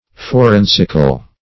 Forensical \Fo*ren"sic*al\, a.